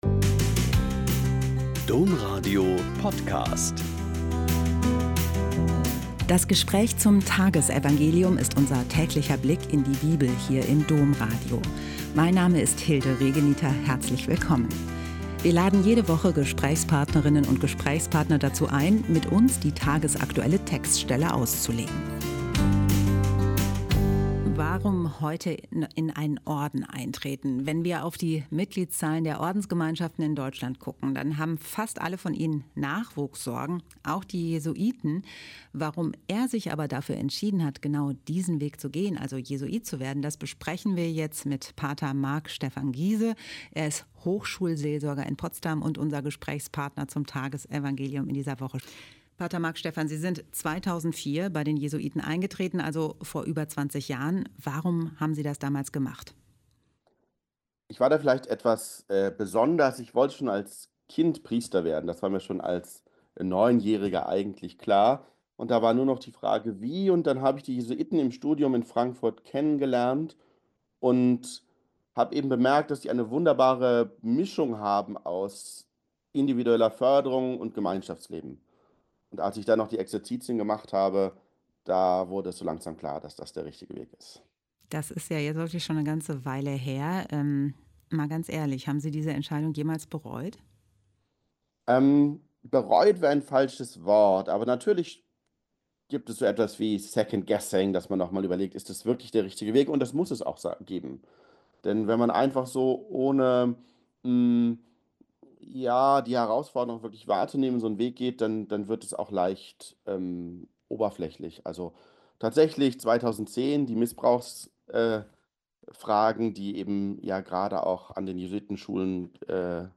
Joh 16,5-11 - Gespräch